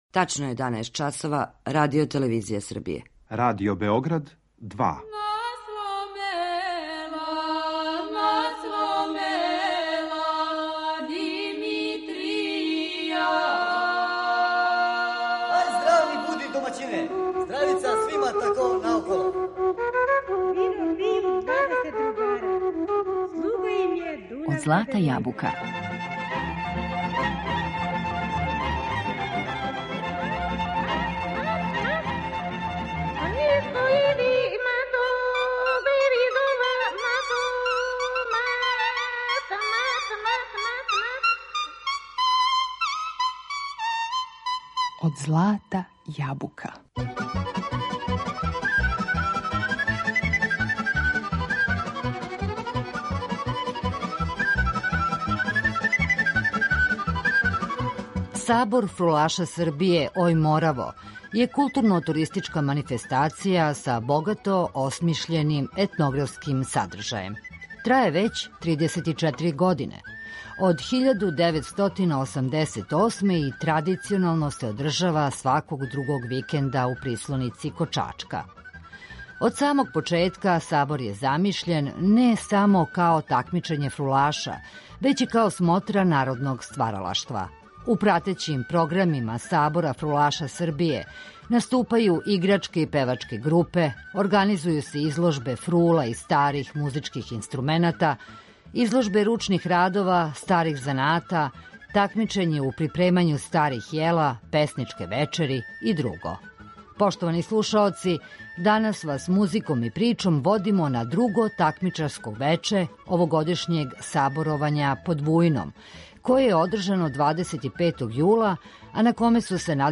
Данас вас музиком и причом у емисији Од злата јабука водимо на друго такмичарско вече овогодишњег саборовања под Вујном, одржано 25. јула, на коме су се надметали најбољи у категорији савременог музицирања.